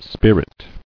[spir·it]